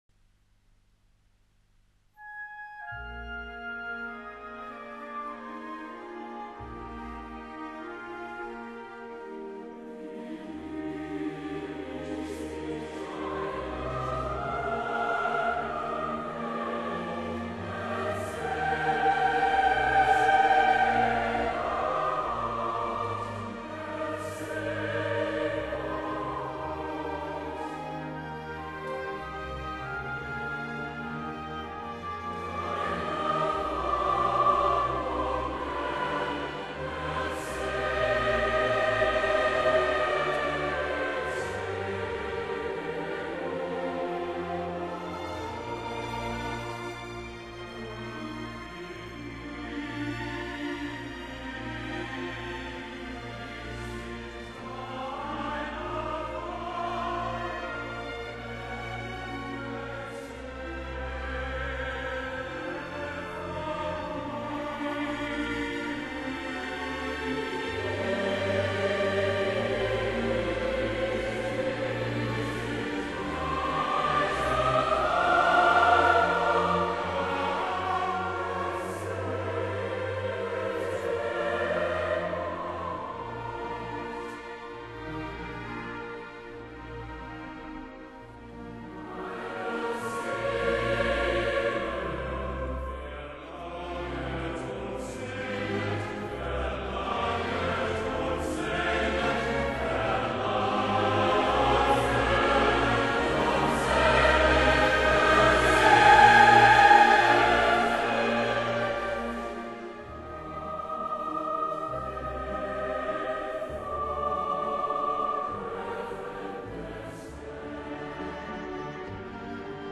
soprano
organ
Chor